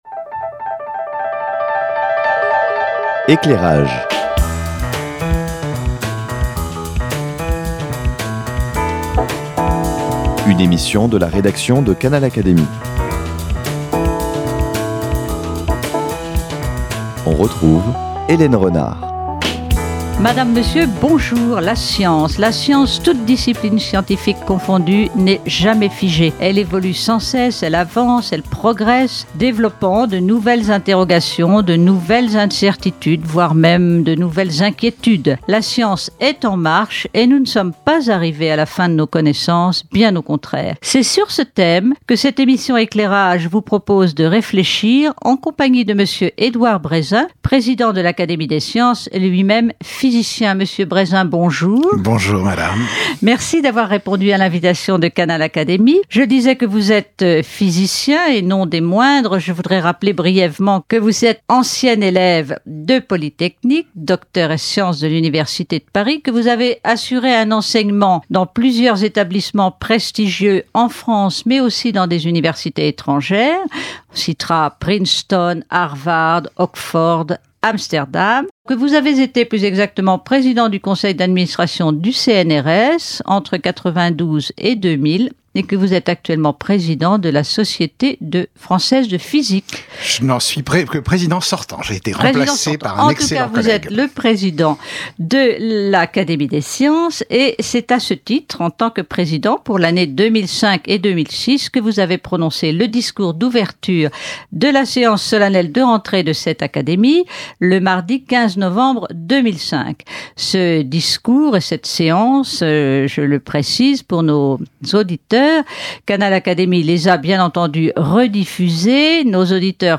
Édouard Brézin, physicien, président de l’Académie des sciences en 2005-2006, présente une dizaine de questions que les diverses disciplines scientifiques n’ont pas encore résolues aujourd’hui.
Ces dix questions reprennent l’essentiel du discours de M. Brézin lors de la séance de rentrée de l’Académie des sciences le 15 novembre 2005. 1.